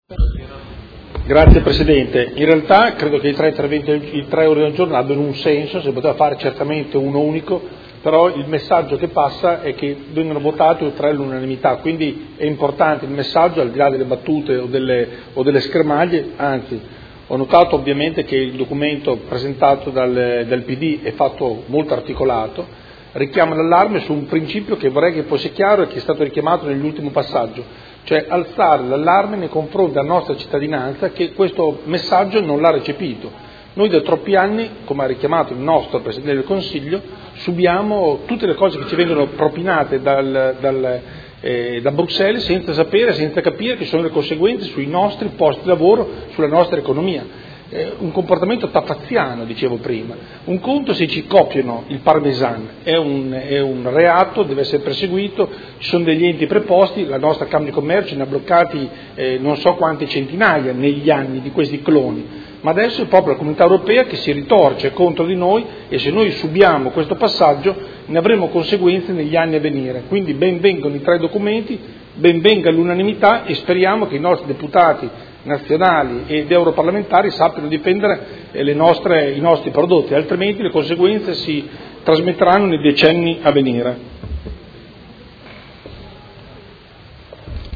Seduta del 28 gennaio. Discussione su ordini del giorno inerenti la problematica lambrusco